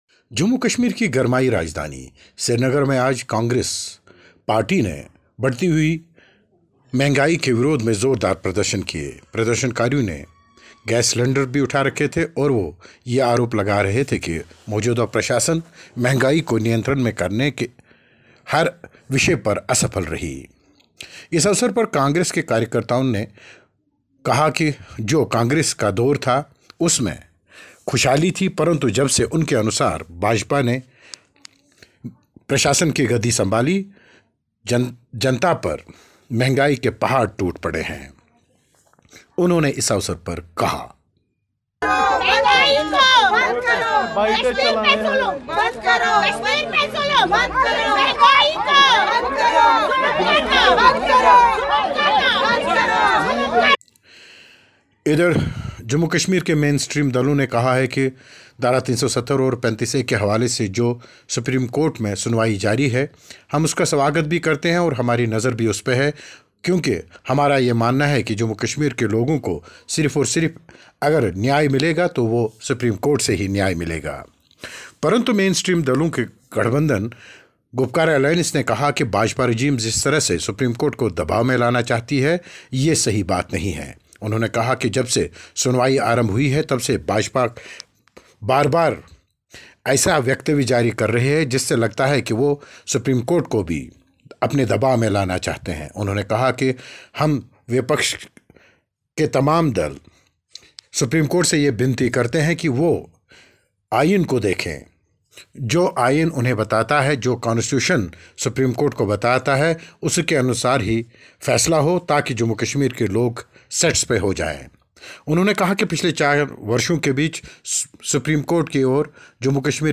श्रीनगर में महंगाई के ख़िलाफ़ कांग्रेस कार्यकर्ताओं का प्रदर्शन, रिपोर्ट